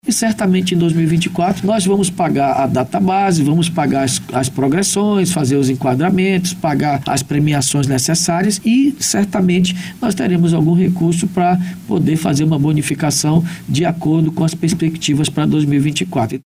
A afirmação ocorreu durante entrevista na BandNews Difusora nessa quarta-feira, 03.